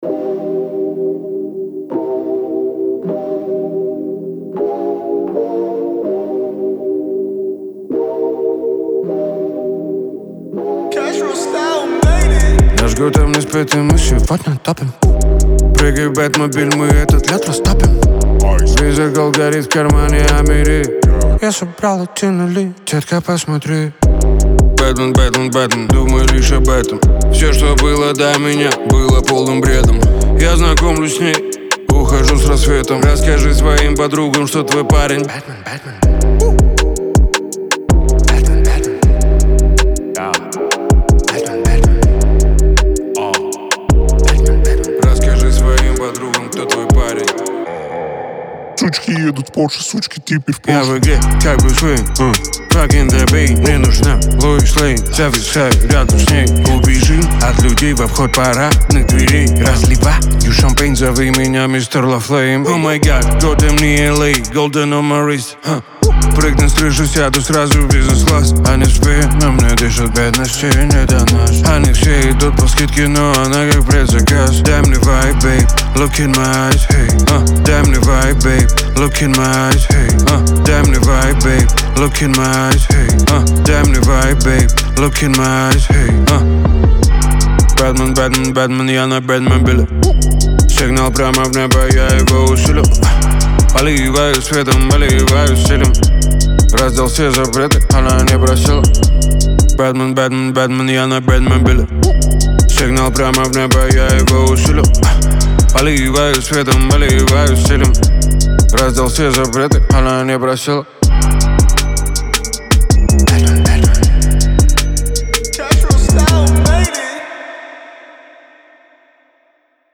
это энергичный трек в жанре хип-хоп